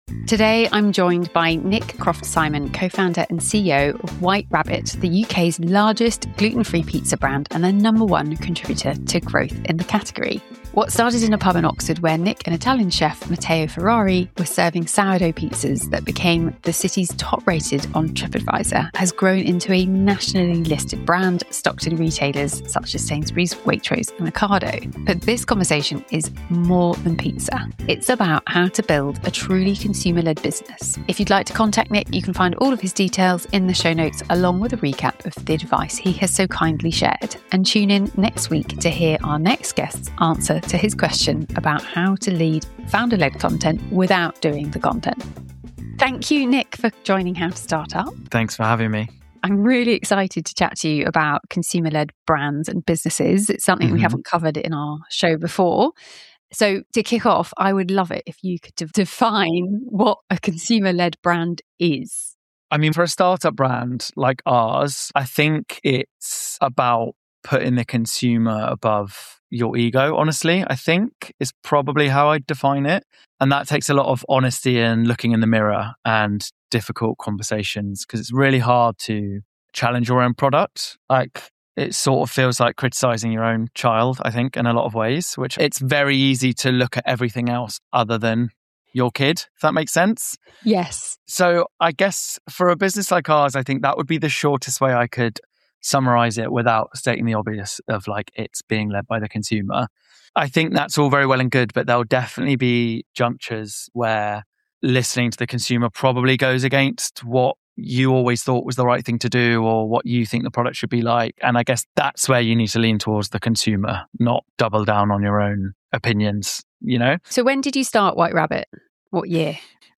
But this is a conversation about more than pizza. It’s about how to build a truly consumer-led business.